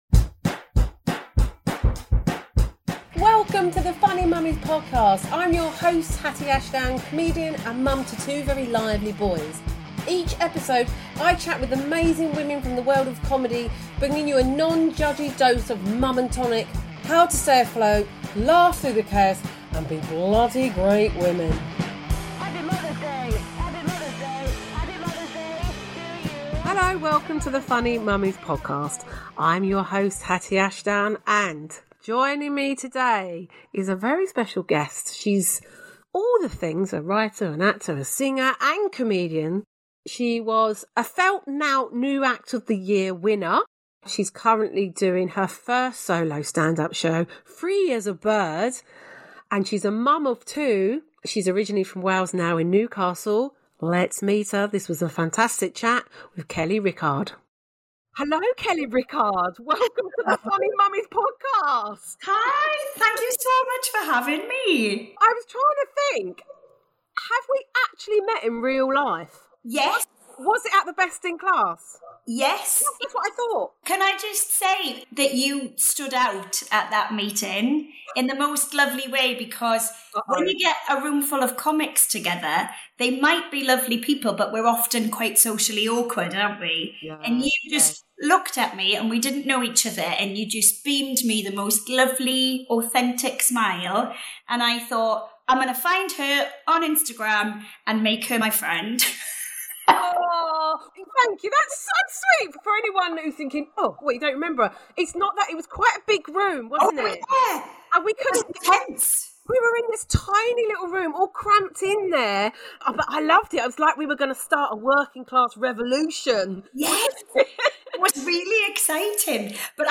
It turns into a big, honest, and funny conversation about class, culture, and motherhood – from the differences (and surprising similarities) between a Welsh upbringing and a Kent one, to the influence of soaps we watched as kids (when we really shouldn’t have been!).